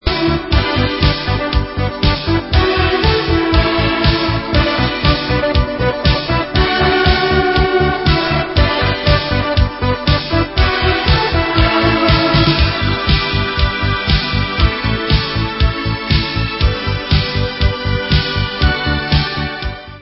Dub Extended